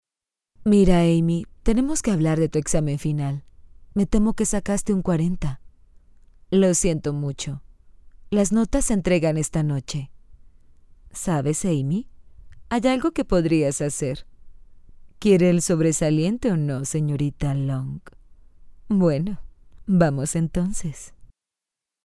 Latin American female voice overs